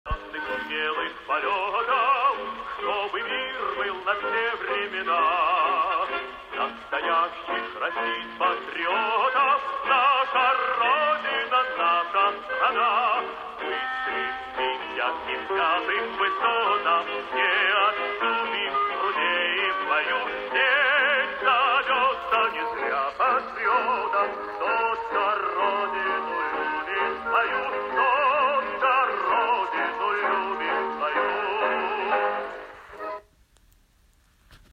Это какой-то марш.